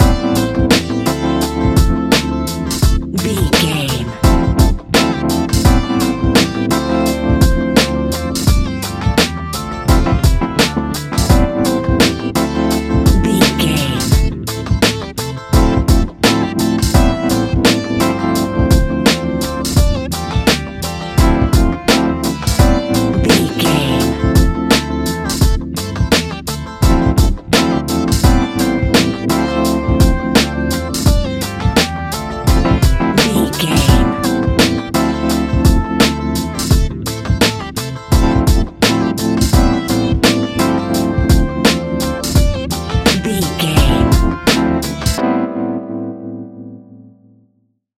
Ionian/Major
A♭
laid back
Lounge
sparse
chilled electronica
ambient